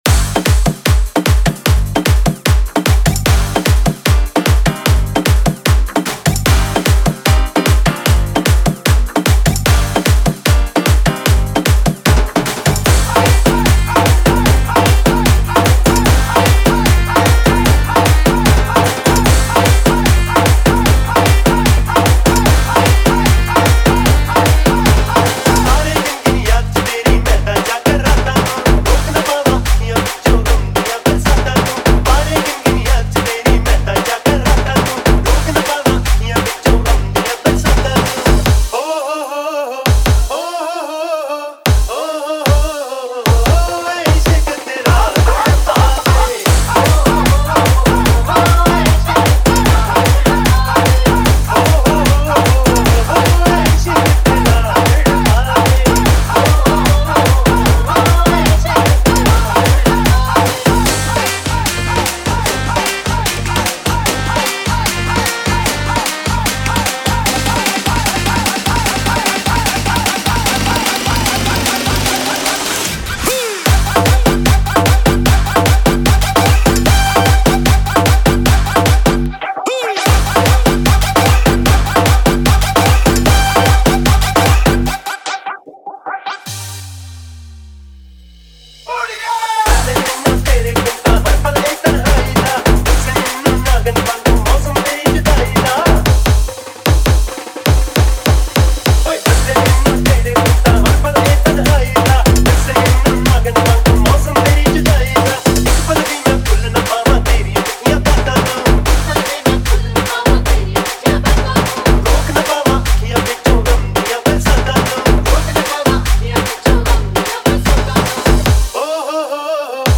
Bollywood Single Remixes